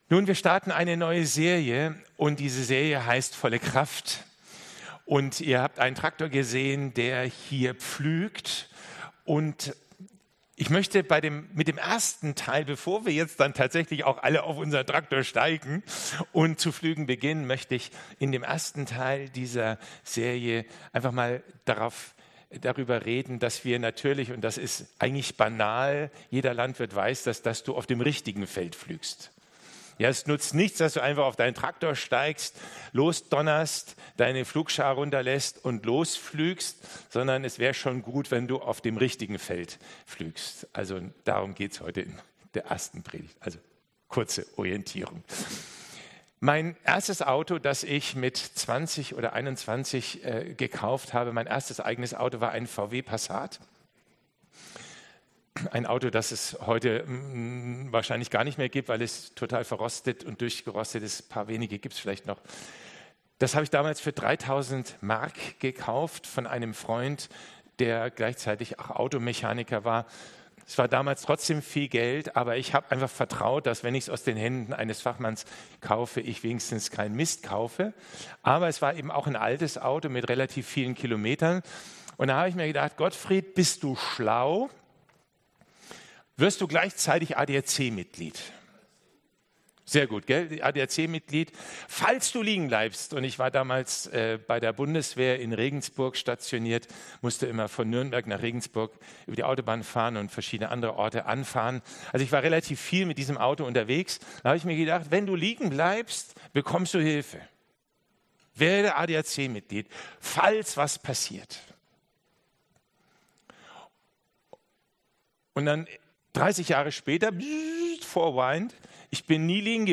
Predigt-Zusammenfassung